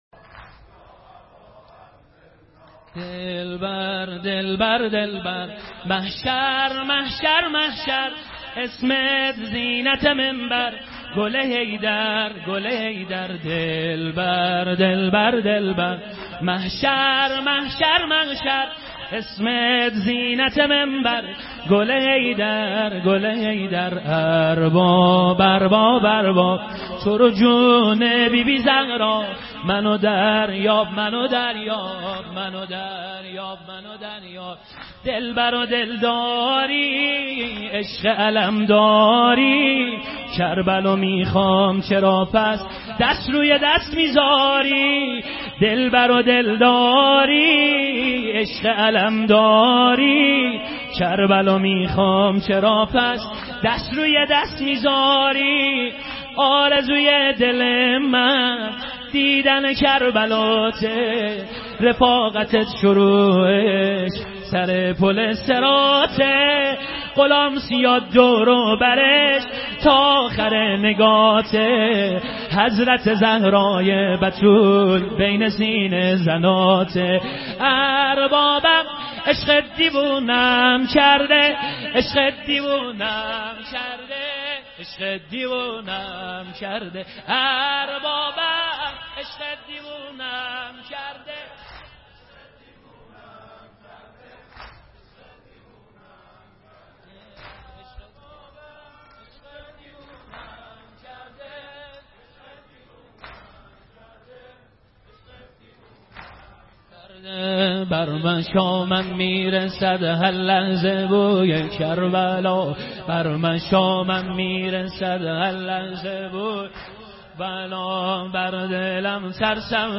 سینه زنی بخش چهارم